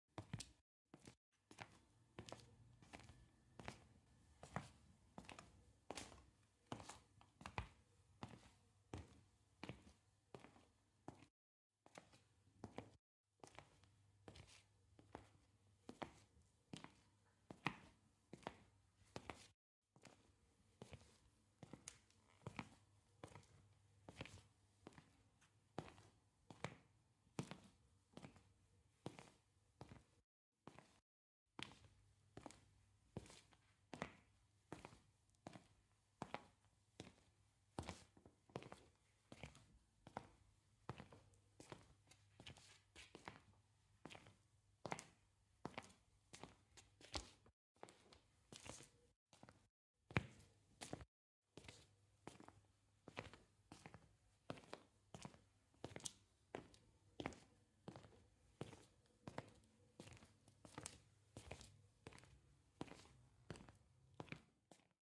دانلود صدای کفش هنگام راه رفتن 5 از ساعد نیوز با لینک مستقیم و کیفیت بالا
جلوه های صوتی